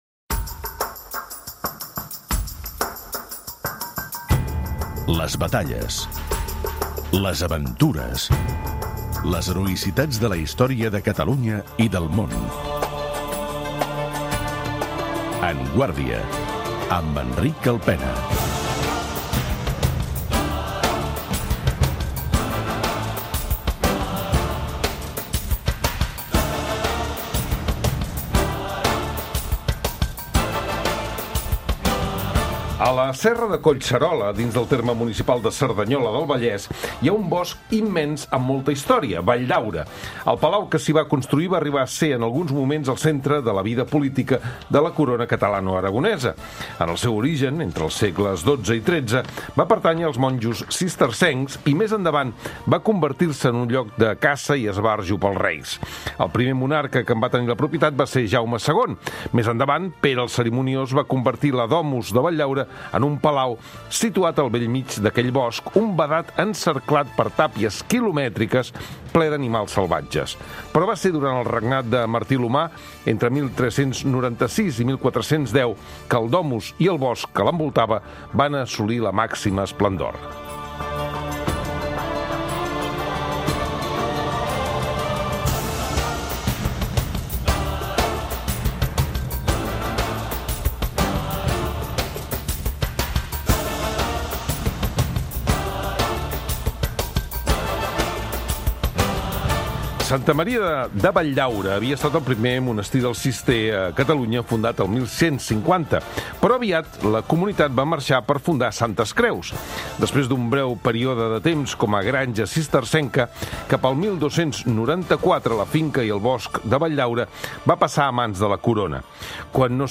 Però va ser durant el regnat de Marti l'Humà, entre 1396 i 1410, que la domus i el bosc que l'envoltava van assolir la màxima esplendor. En parlem amb Josep Maria Solé i Sabaté, historiador,